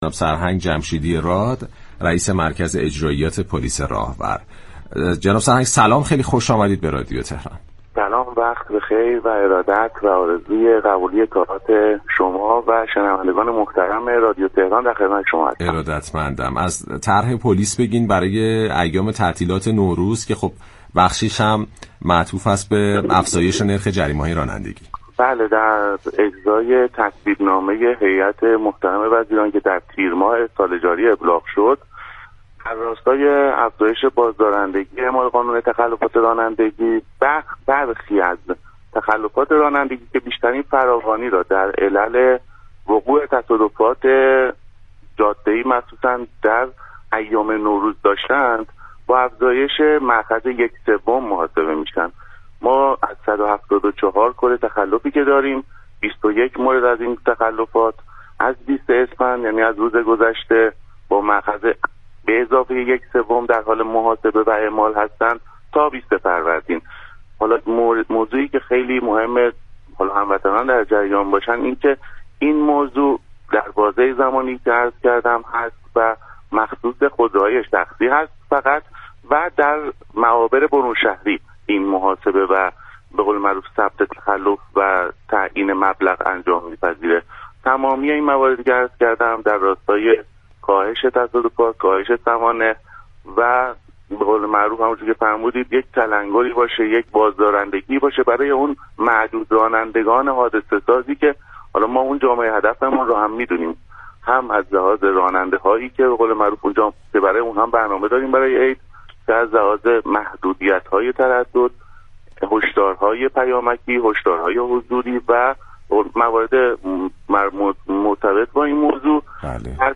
به گزارش پایگاه اطلاع رسانی رادیو تهران، سرهنگ جلیل جمشیدی راد رئیس اجرائیات پلیس راهور فراجا در گفت و گو با «بام تهران» اظهار داشت: در راستای مصوبه هیات وزیران كه از تیرماه سال جاری ابلاغ شد، برخی از تخلفات رانندگی كه بیشترین فراوانی در وقوع تصادف‌های جاده‌ای به ویژه در ایام نوروز را داشتند افزایش قیمت داشت.